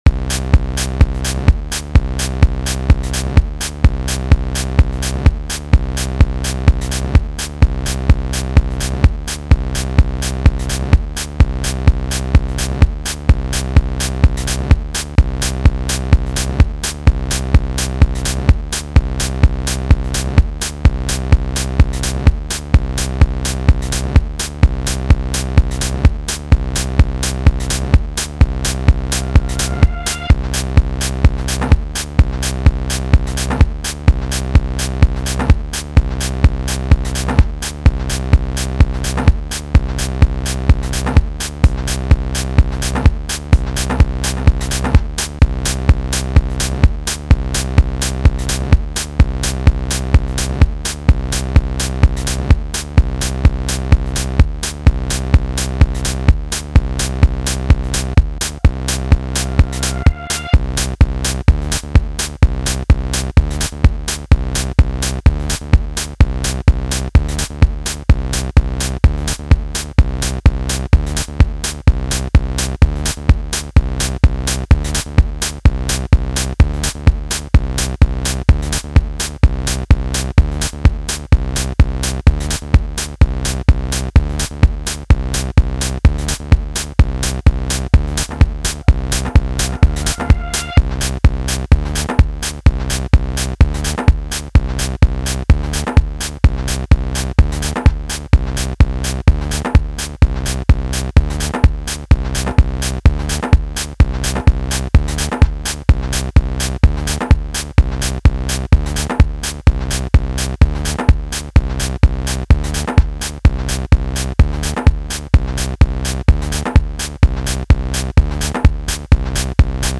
Sequencing as simple as possible.